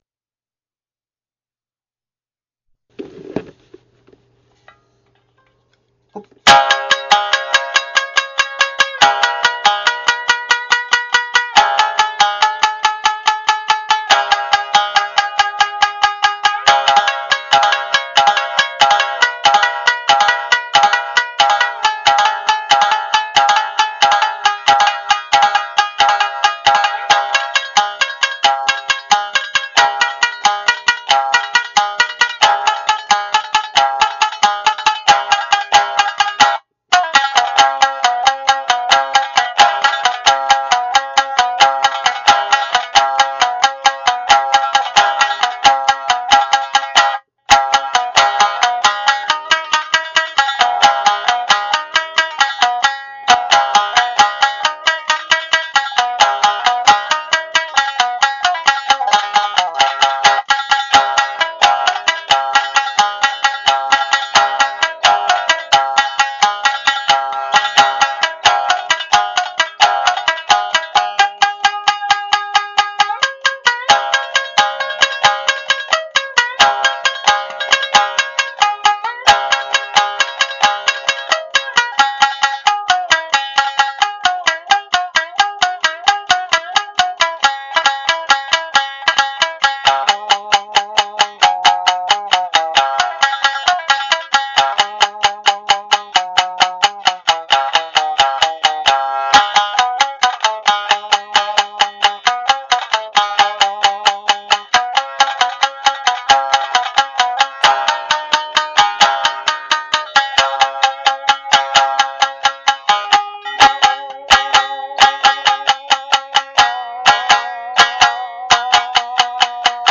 Instrument:Tsugaru Shamisen